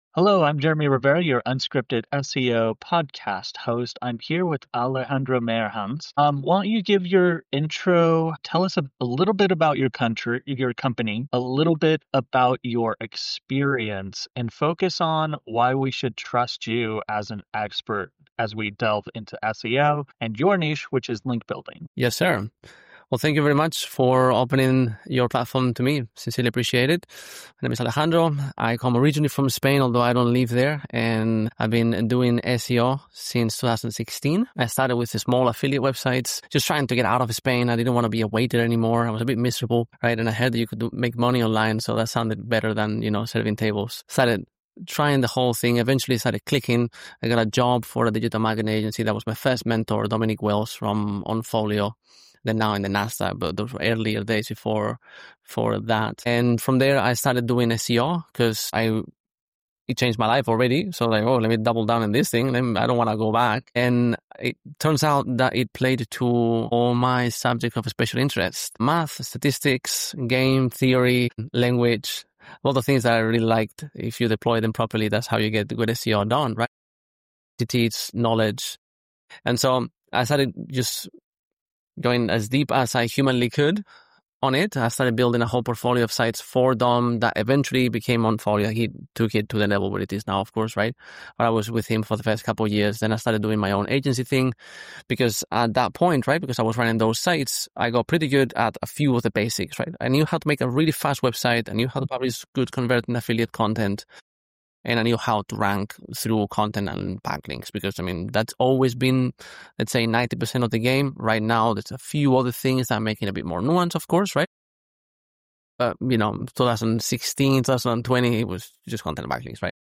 In this deep-dive conversation